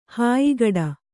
♪ hāyigaḍa